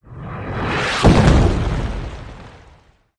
meteorite.mp3